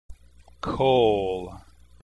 1. Vocabulary word #1 is pronounced "Kol" (coal), not "Kal" as it appears. The "a" vowel is pronounced "o" in this word.